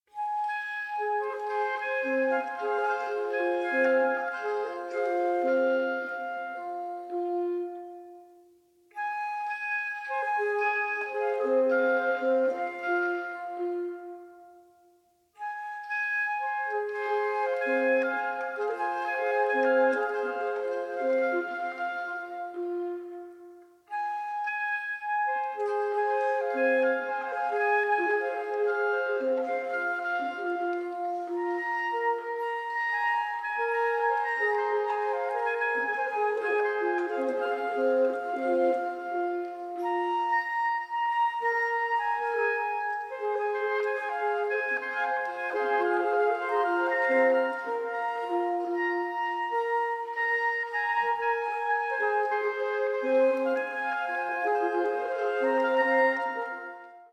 flute
oboe, cor anglais
clarinet
bassoon
French horn